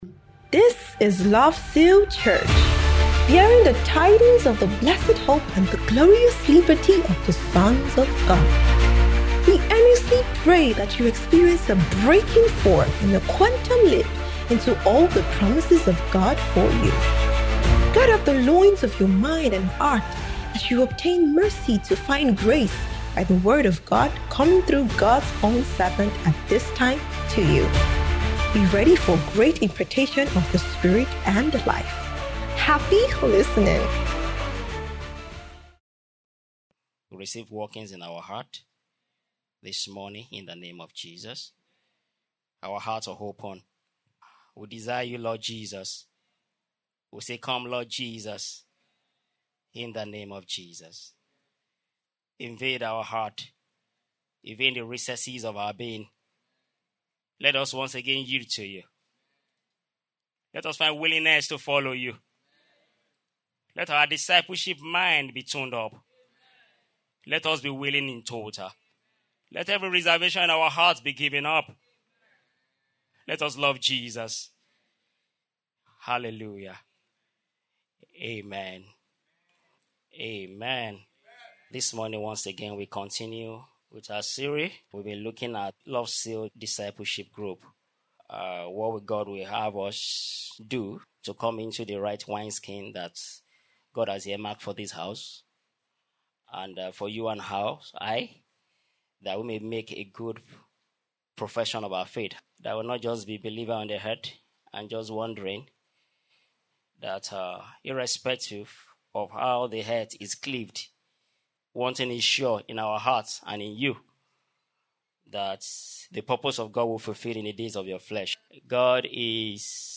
Kingdom Believers’ Community Service